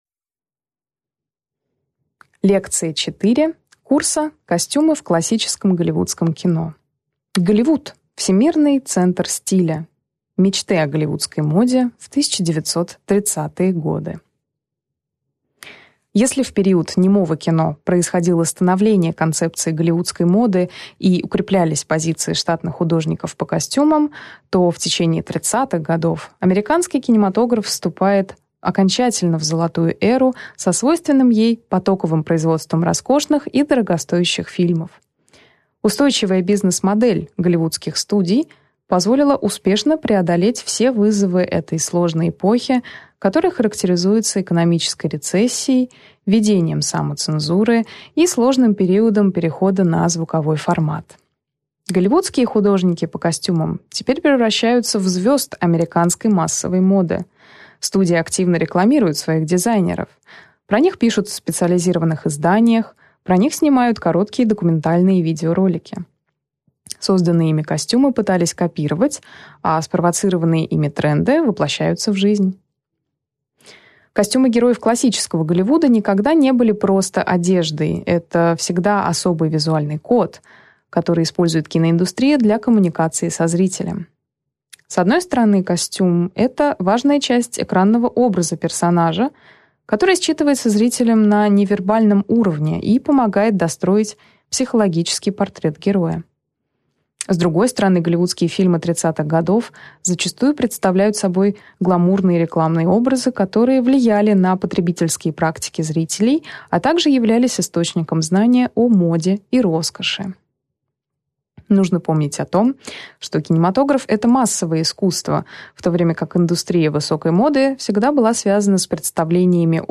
Аудиокнига Голливуд, всемирный центр стиля: мечты о голливудской моде в 1930-е годы.